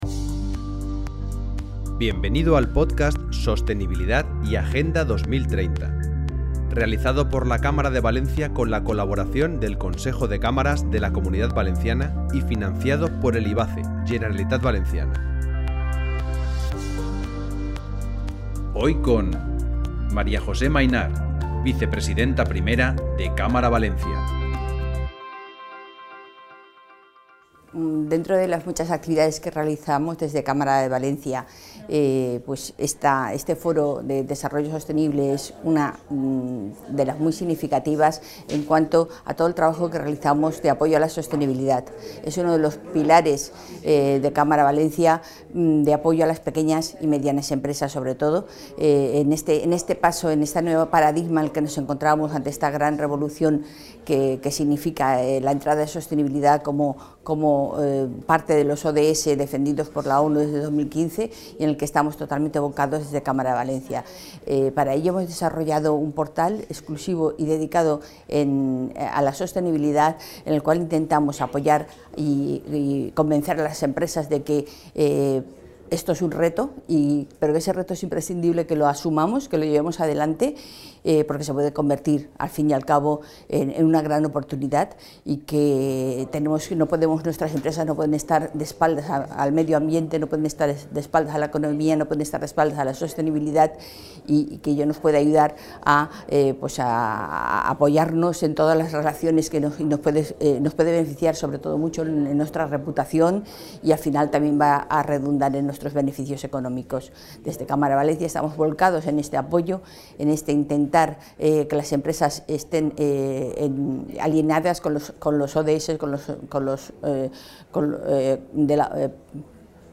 Con el podcast “La revolución 4.0” pretendemos realizar una foto desde dentro de las propias compañías, entrevistando a los CEO, directivos y/o responsables de la transformación digital de un gran número de empresas de Valencia, algunas de la Comunidad Valencia y también del territorio nacional.